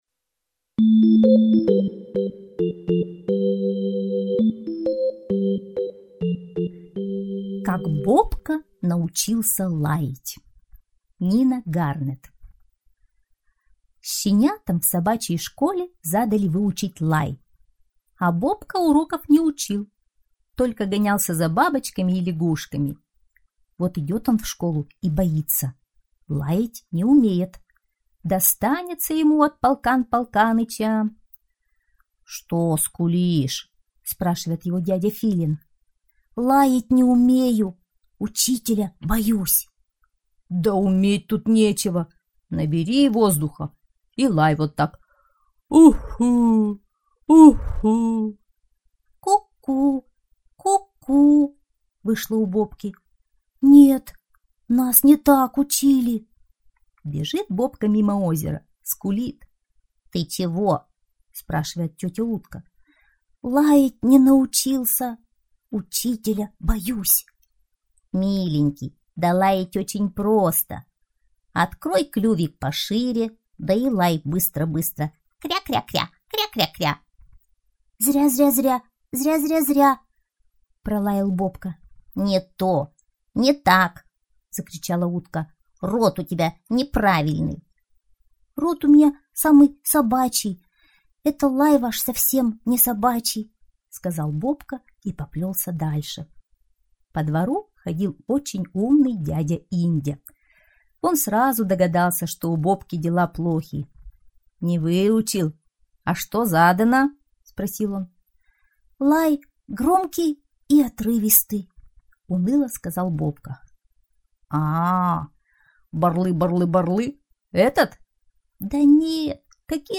Как Бобка научился лаять - аудиосказка Гернет - слушать